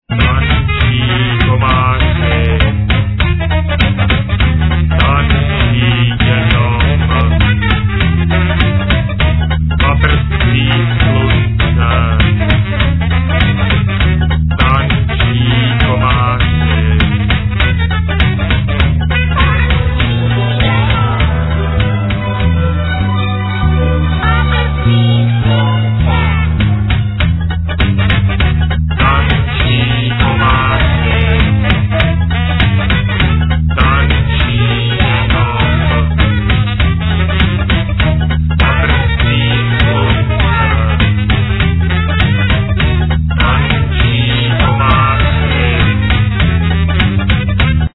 Bass guitar, Sequencer, Sbor
Trumpet
Violin